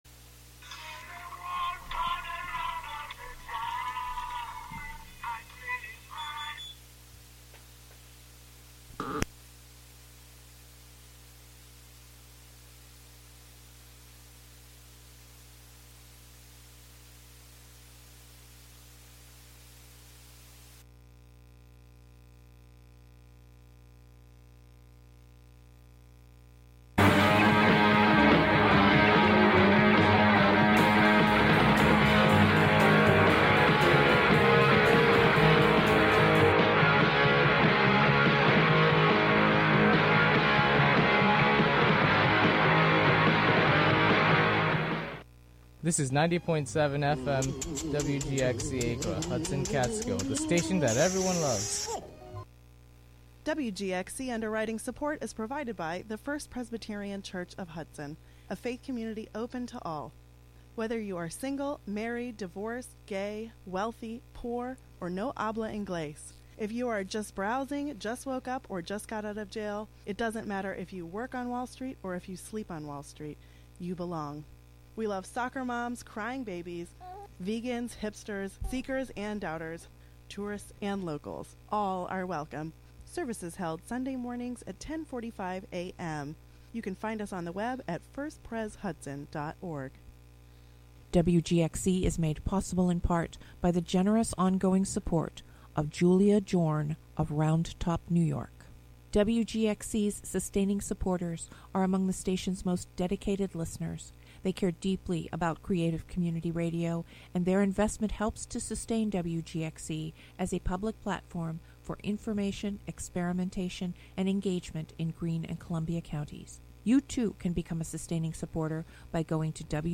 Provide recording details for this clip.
Broadcast live from WGXC's Hudson studio.